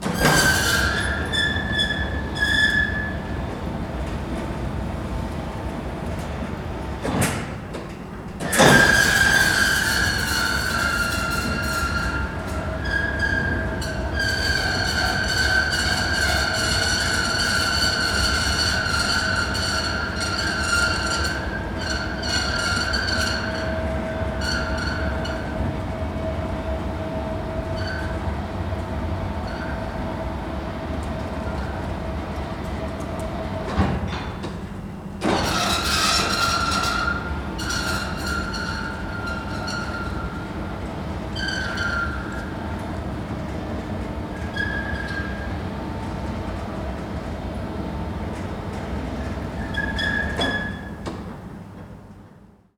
crane.L.wav